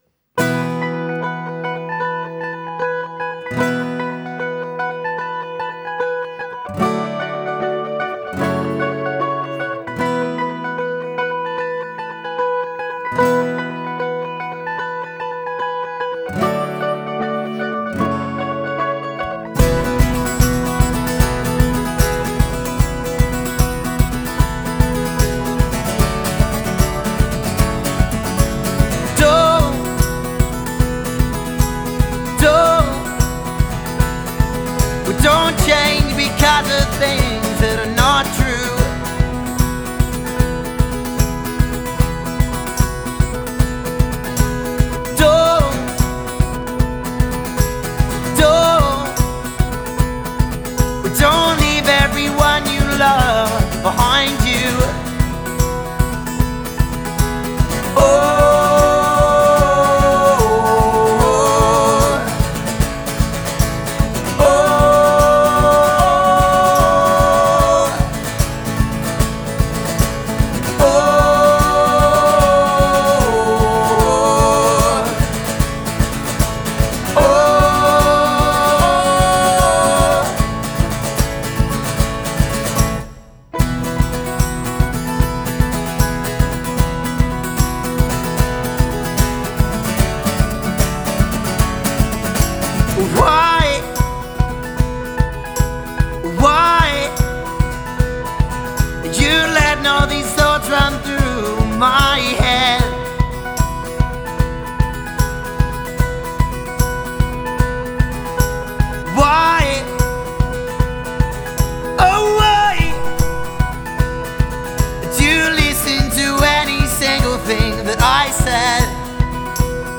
Harmony Vocals | Guitar | Banjo | Bass